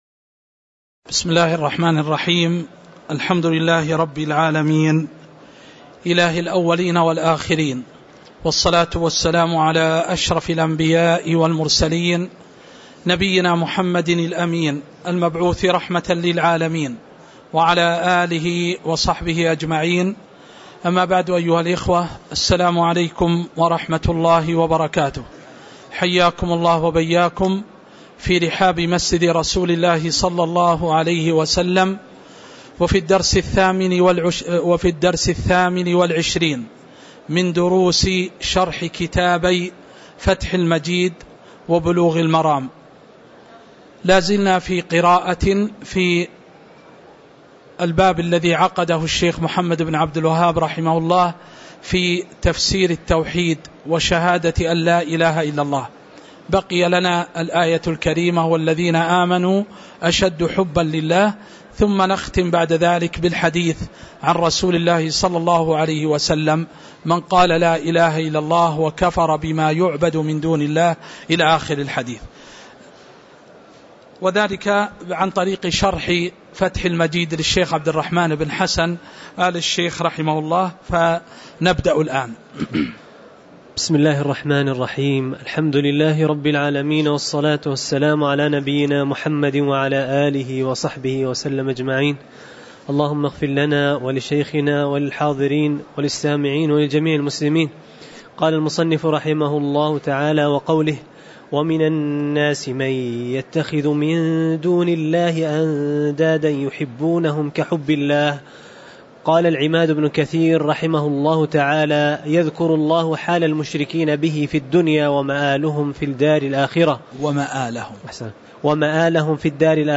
تاريخ النشر ١٤ ذو الحجة ١٤٤٤ هـ المكان: المسجد النبوي الشيخ